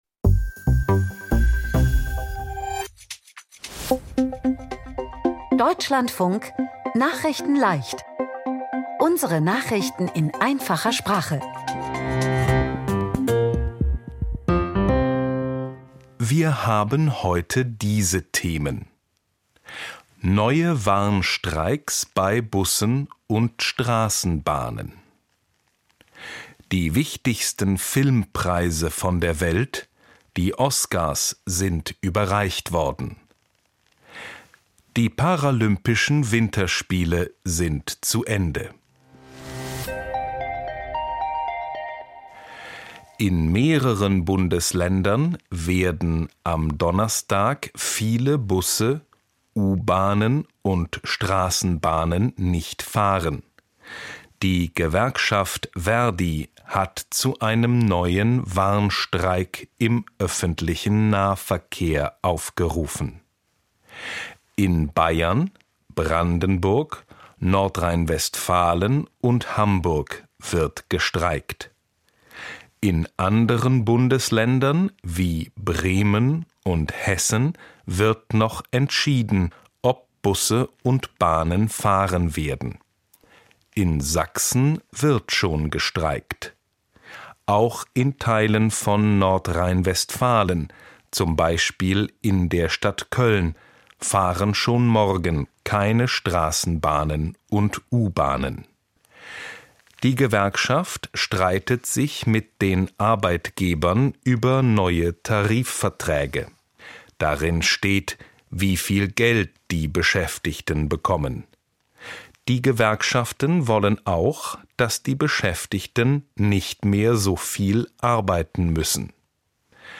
Nachrichtenleicht - Nachrichten in Einfacher Sprache vom 16.03.2026